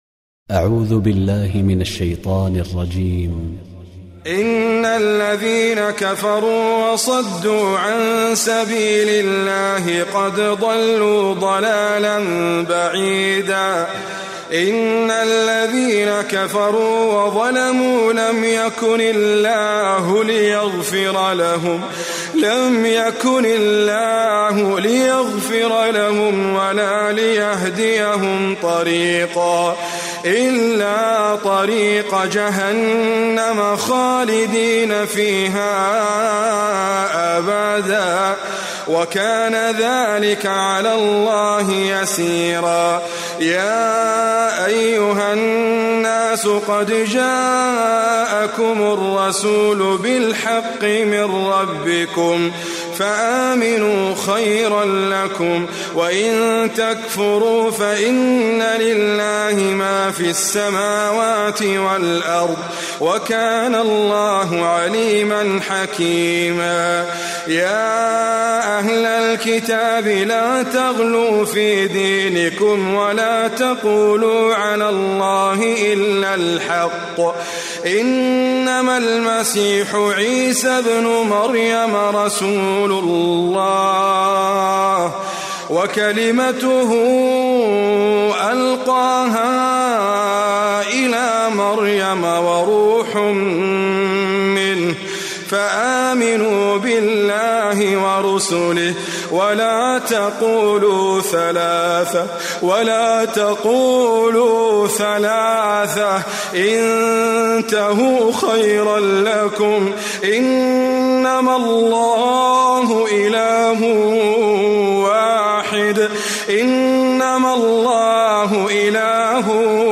Recitime
Idriss Abkar